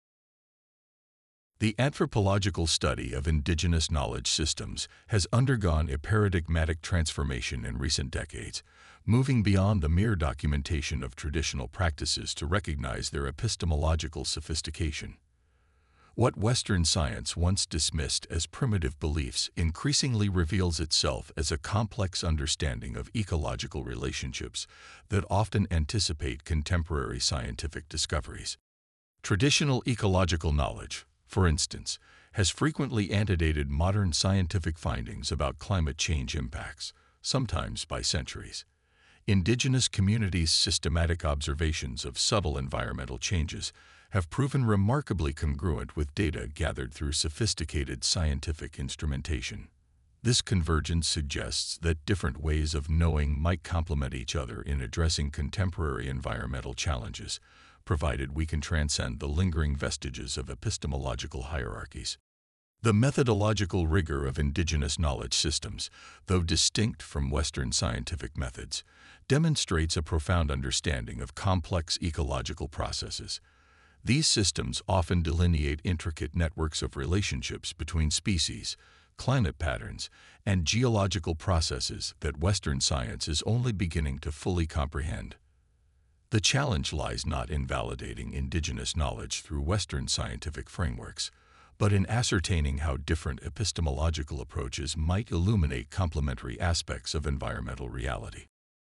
【朗読用音声】B